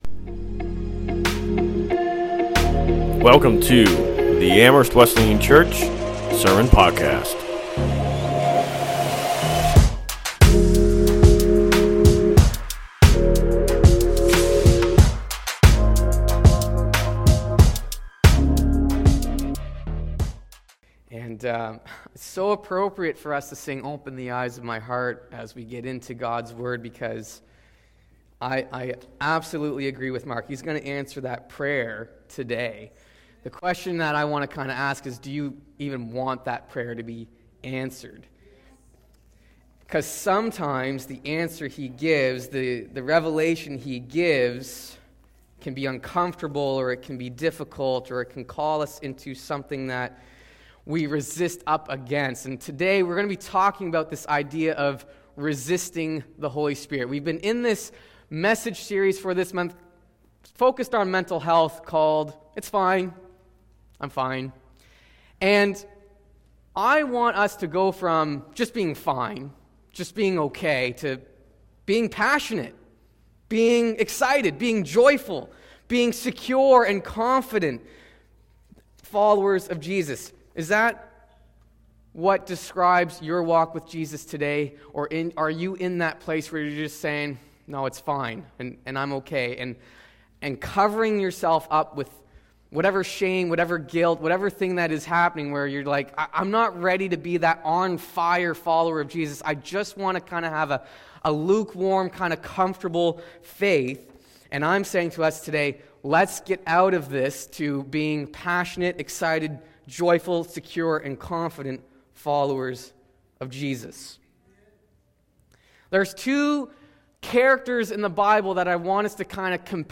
2025 Current Sermon The Darkness Inside If you resist God, darkness will consume you from the inside out.